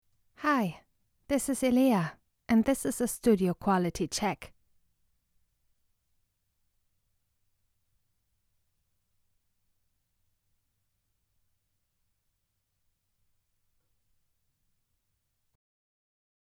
Female
TEENS, 20s, 30s
Bright, Character, Confident, Energetic, Friendly, Young
Voice reels
Microphone: Shure SM7b, Rode-NT1a